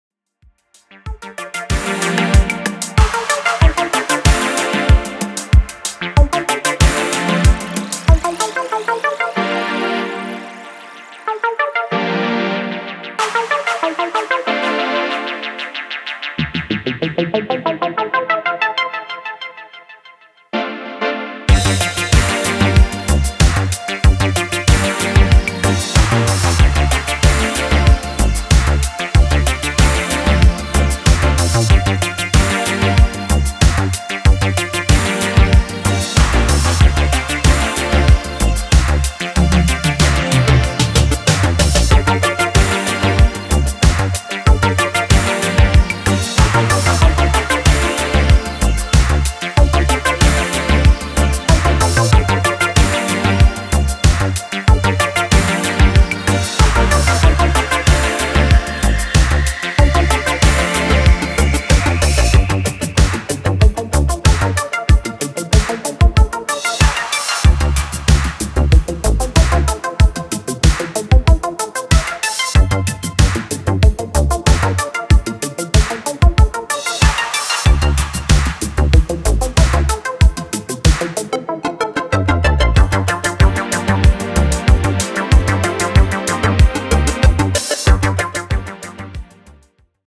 proto-house, boogie and zouk